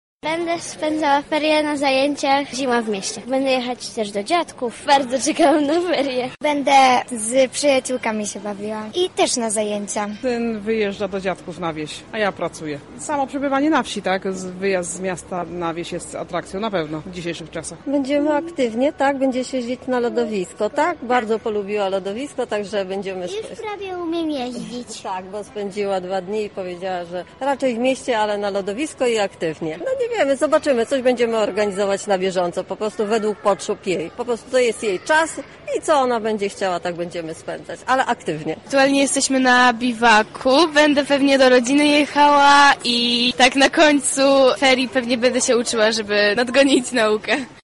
O to jak Lublinianie zamierzają spędzić ferie w tym roku zapytali nasi reporterzy: